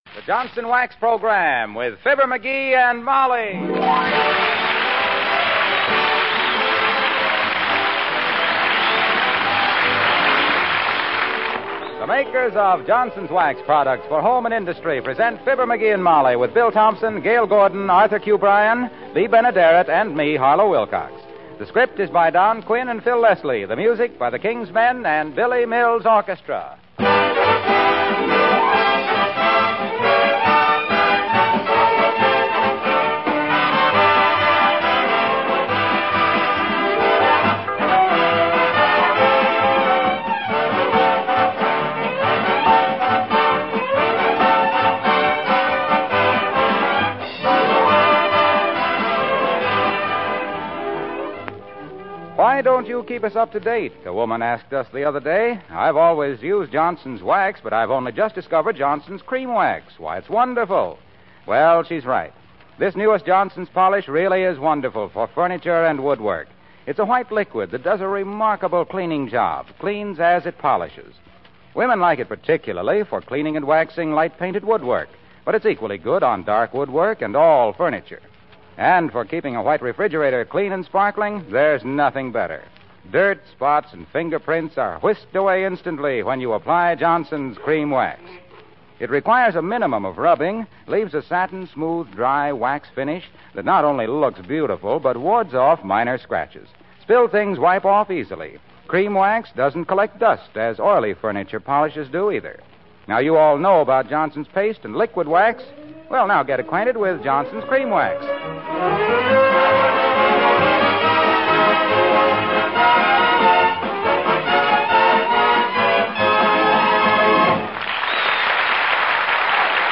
Fibber McGee and Molly was an American radio comedy series.